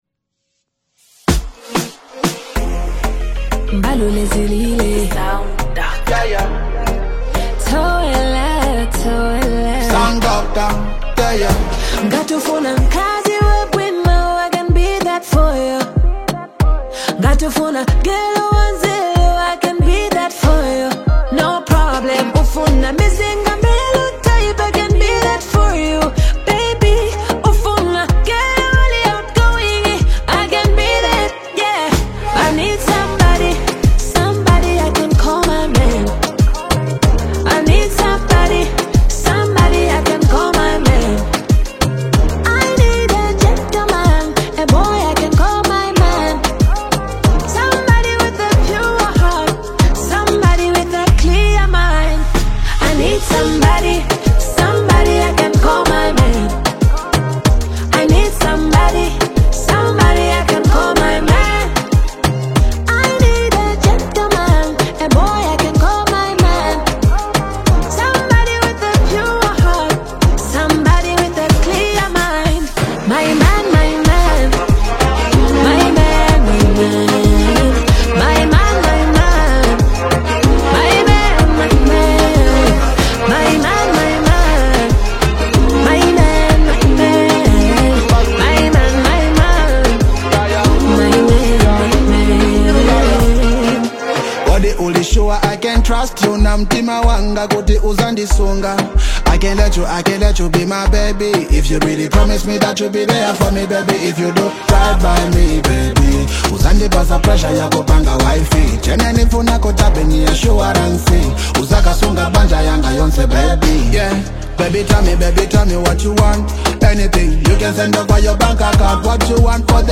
smooth Afro-Pop/R&B love single